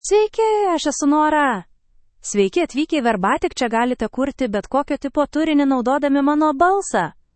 NoraFemale Lithuanian AI voice
Nora is a female AI voice for Lithuanian (Lithuania).
Voice sample
Listen to Nora's female Lithuanian voice.
Female
Nora delivers clear pronunciation with authentic Lithuania Lithuanian intonation, making your content sound professionally produced.